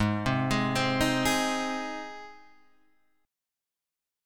G# Major